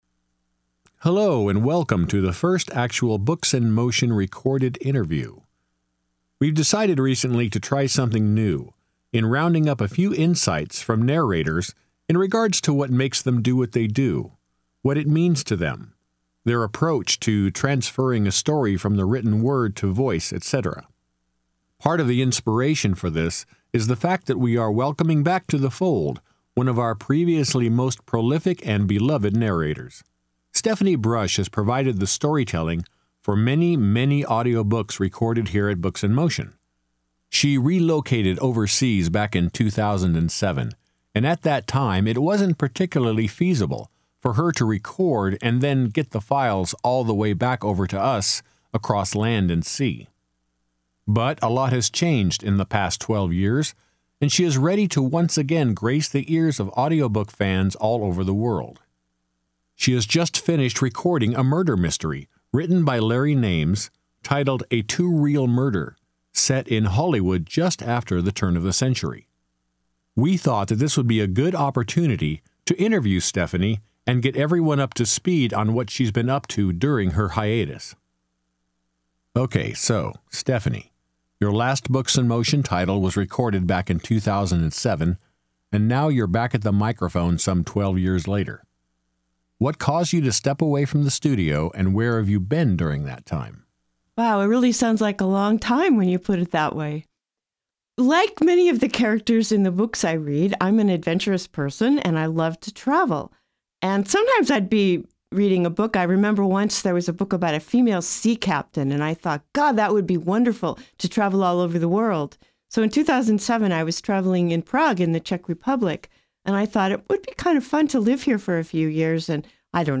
🌟 Narrator Spotlight: